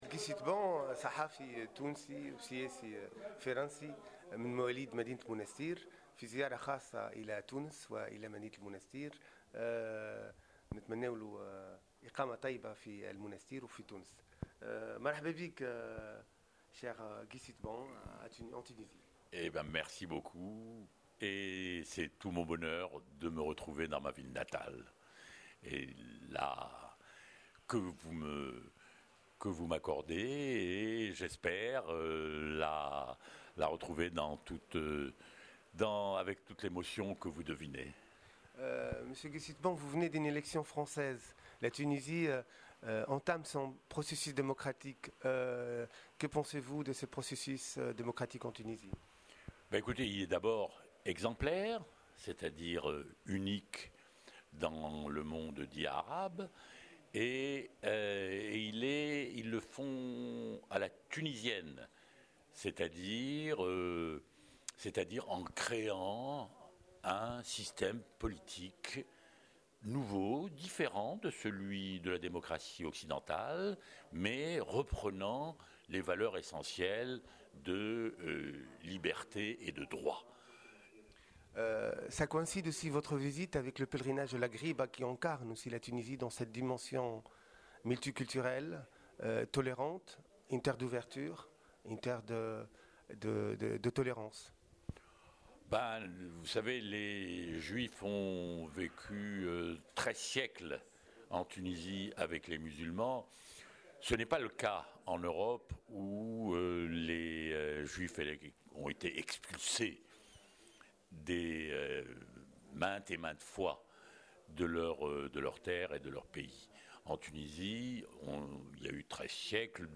الصحفي التونسي الفرنسي Guy Sitbonيتحدث بتأثر عن المنستير المدينة الأم